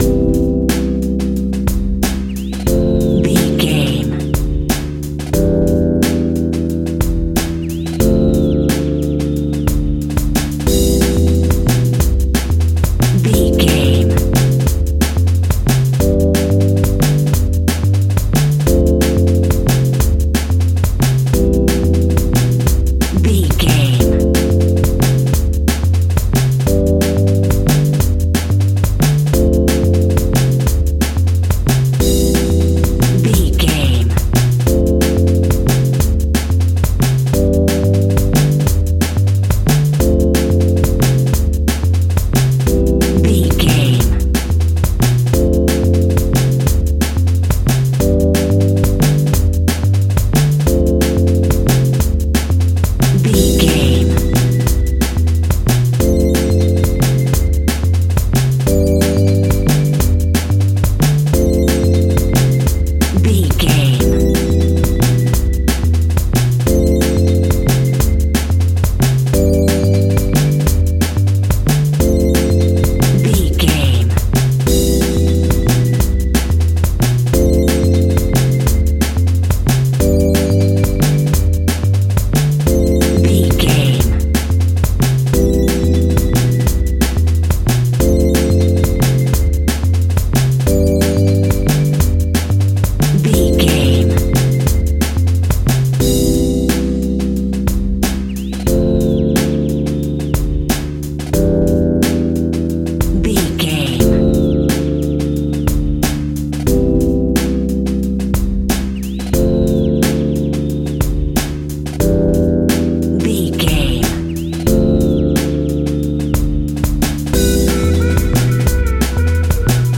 In-crescendo
Ionian/Major
Fast
driving
energetic
uplifting
futuristic
hypnotic
frantic
drum machine
synthesiser
sub bass
synth leads